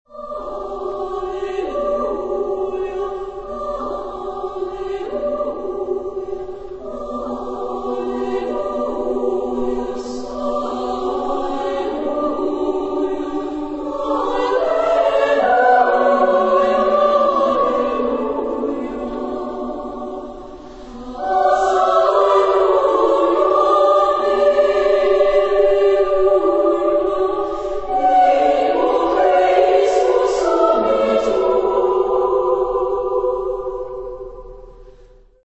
Genre-Style-Forme : Motet ; Sacré
Type de choeur : SSAA  (4 voix égales de femmes )
Tonalité : si mineur